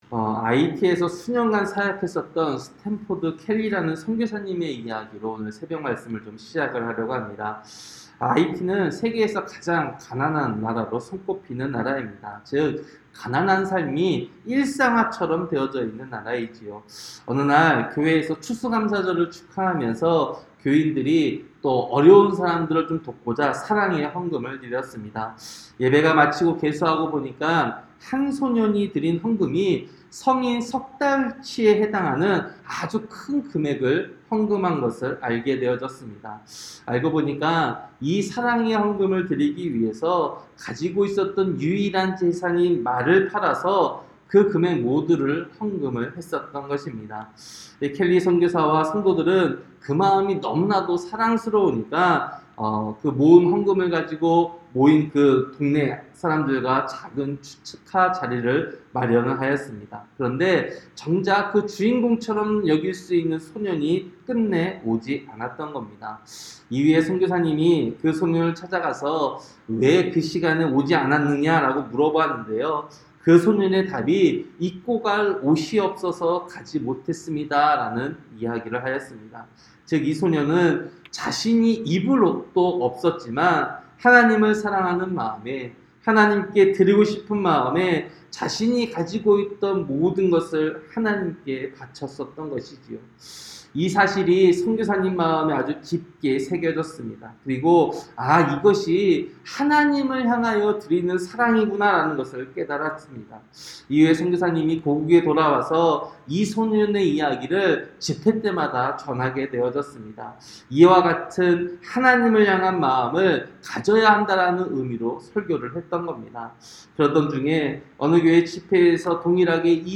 새벽설교-사사기 17장